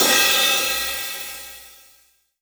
Index of /90_sSampleCDs/AKAI S6000 CD-ROM - Volume 3/Crash_Cymbal1/18_22_INCH_CRASH
DRY 18SZL1-S.WAV